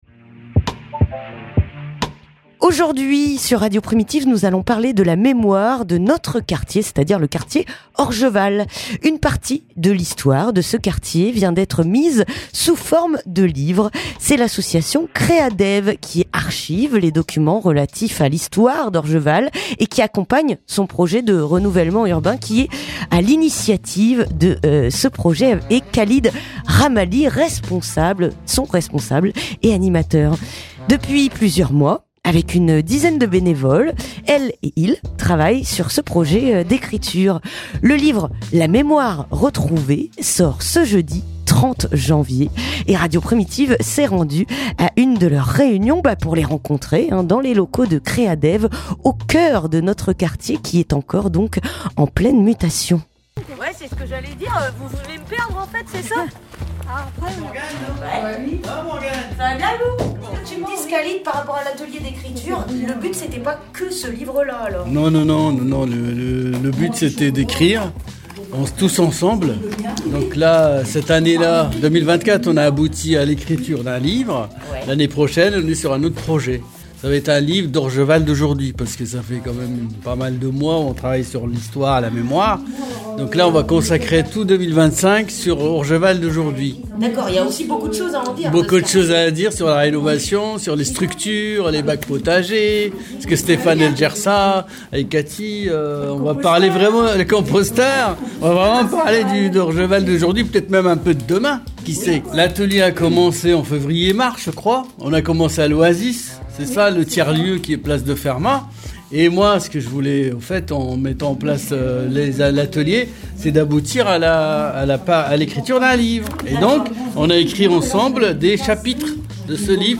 Reportage à Créadev (15:14)
Radio Primitive est allée rencontrer les rédactrices lors de l'un de leur rendez-vous hebdomadaire dans les locaux de l'association, qui oeuvre à transmettre l'histoire d'Orgeval.